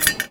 R - Foley 179.wav